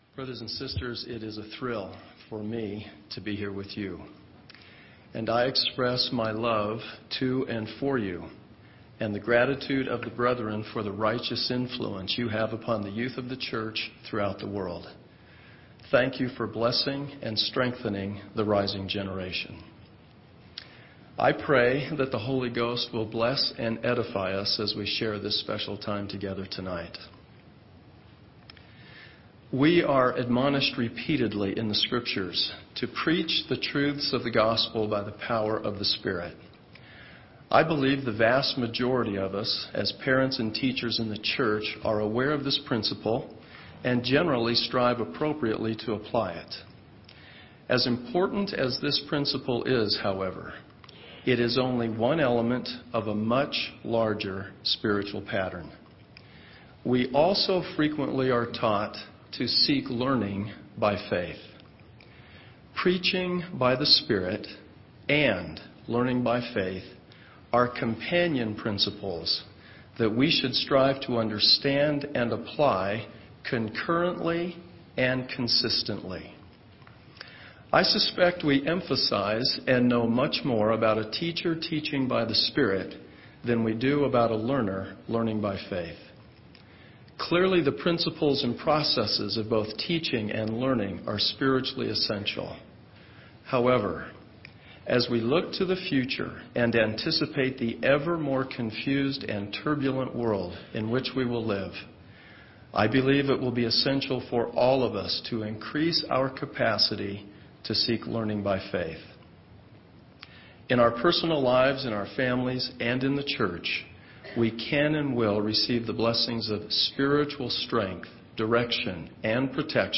(BYU Devotional) Elder David A Bednar- Seek Learning By Faith